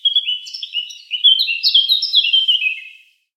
На этой странице собраны натуральные звуки чириканья птиц в высоком качестве.
Птица в лесной чаще поет мелодию